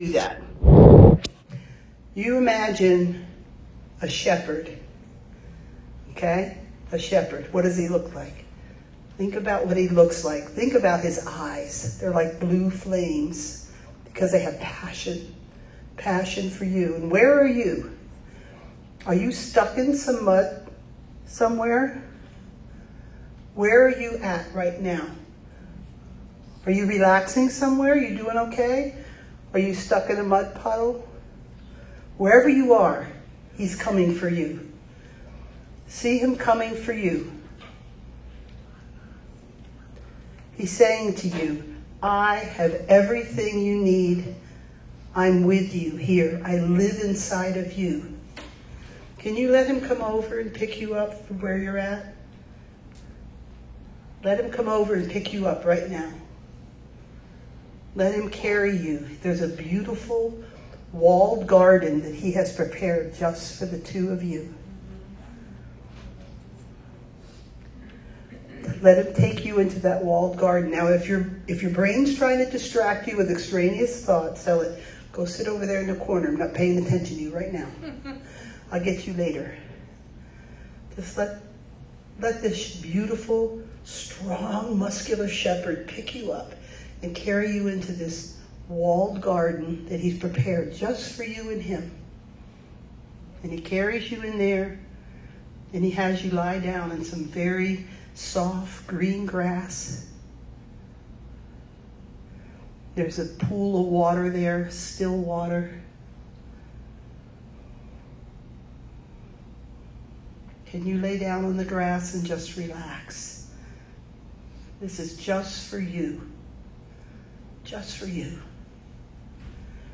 Run with Chariots Women’s Retreat
The Father’s House in Conyers, GA